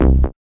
5808R BASS.wav